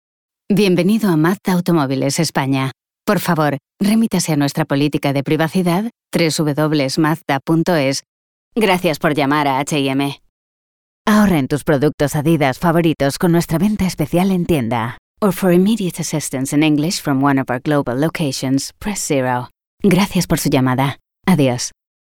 Veelzijdig, Zakelijk, Commercieel, Vriendelijk, Natuurlijk
Telefonie